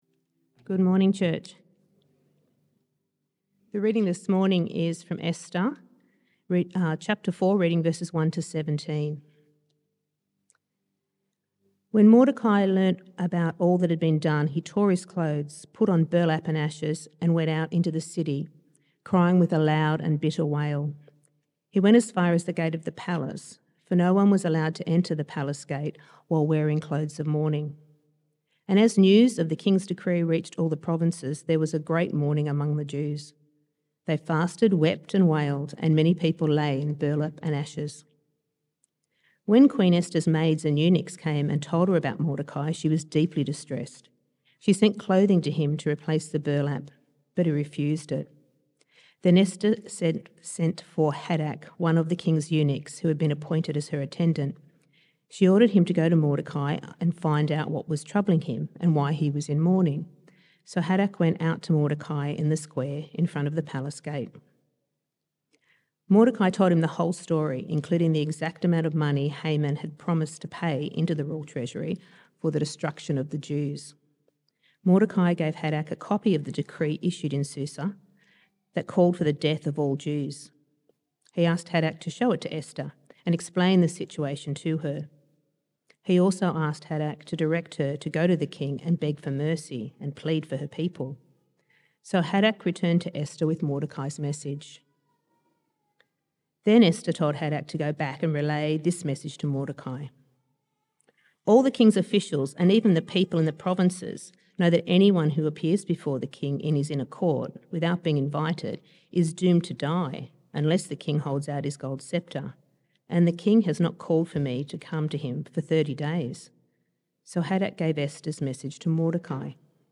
September Sermons